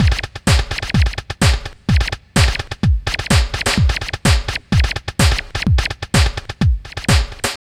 07.1 LOOP2.wav